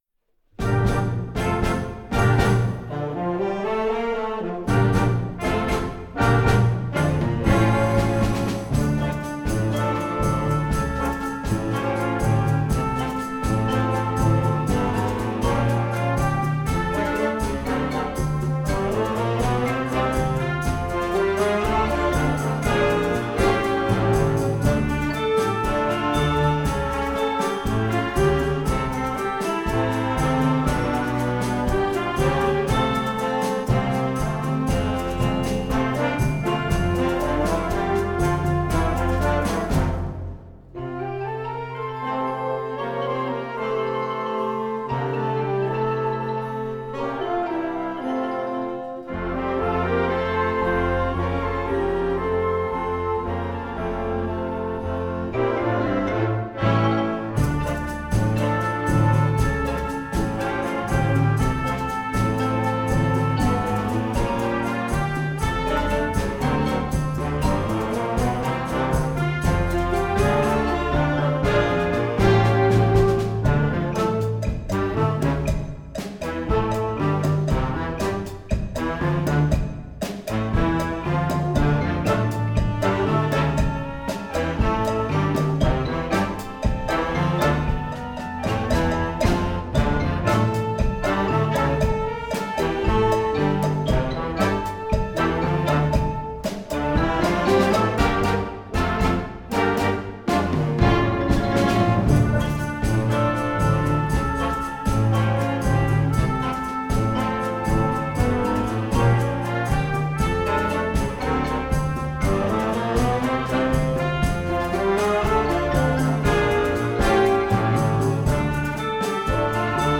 Voicing: Concert Band with Vocals